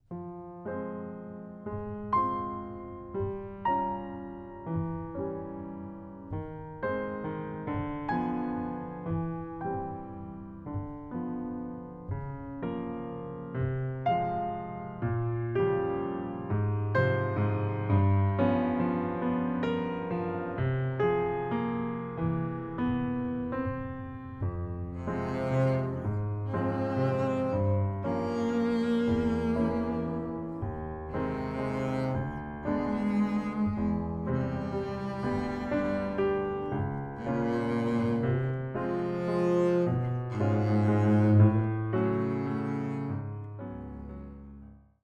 Recorded on July.26th 2022 at Studio Happiness Engineer
vln